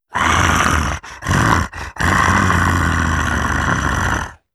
Monster Roars
14. Guttural Growl.wav